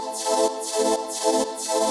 SaS_MovingPad02_125-A.wav